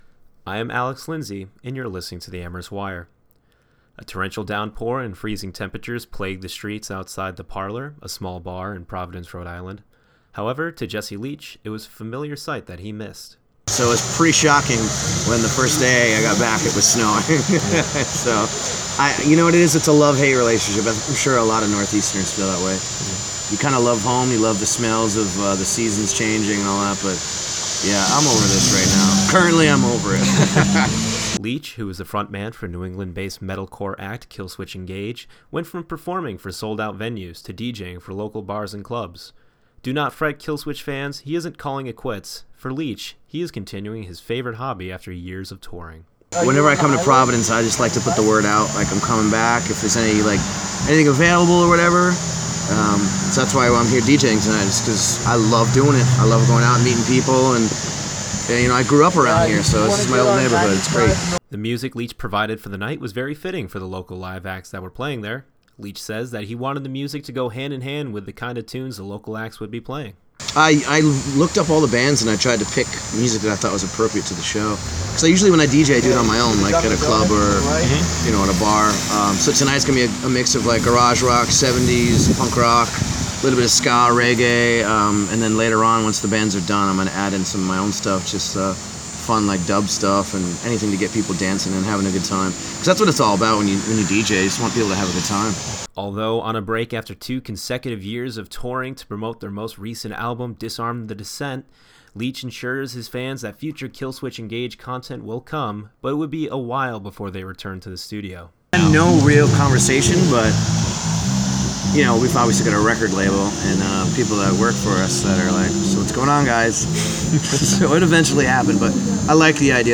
It was a frigid and rainy December evening outside The Parlour in Providence, R.I., the type of weather that Jesse Leach, frontman for Killswitch Engage, knows very well.